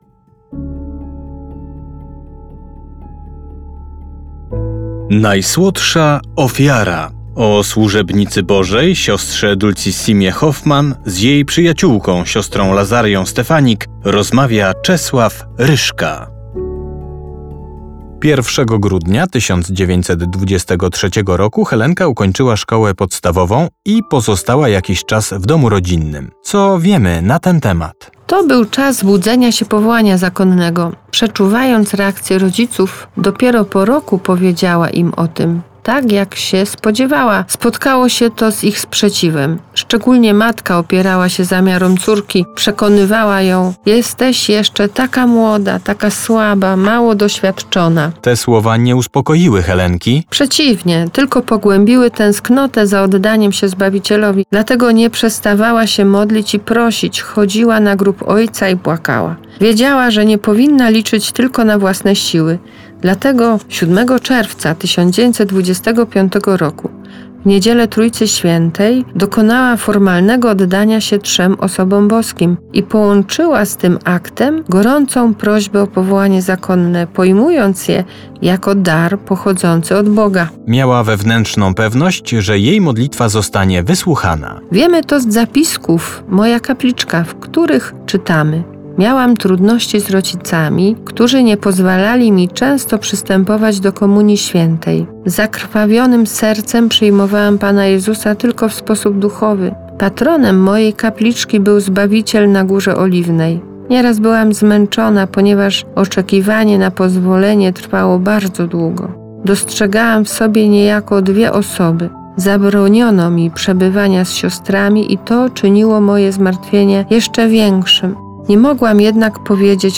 Propozycją duszpasterską Radia Rodzina na Wielki Post jest specjalny audiobook pt. „Dulcissima -Najsłodsza Ofiara”.